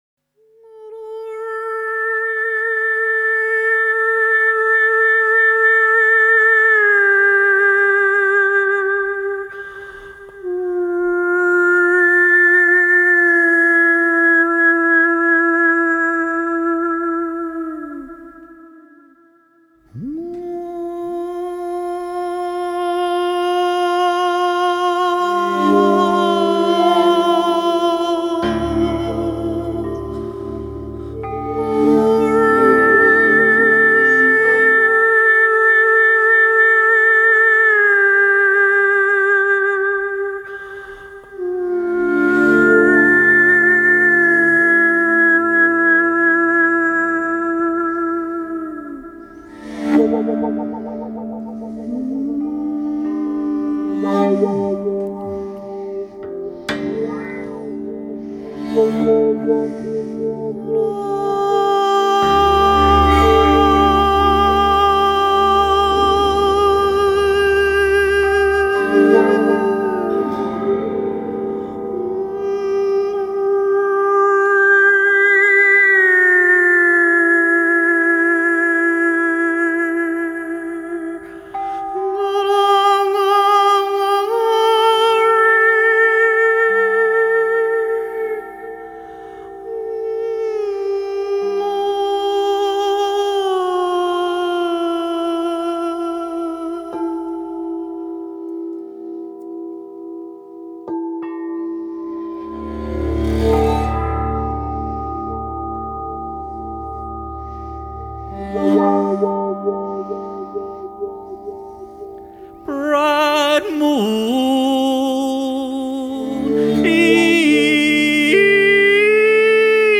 Gendung (Indonesian Drums)
Xylopt and drum kit